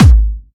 VEC3 Clubby Kicks
VEC3 Bassdrums Clubby 040.wav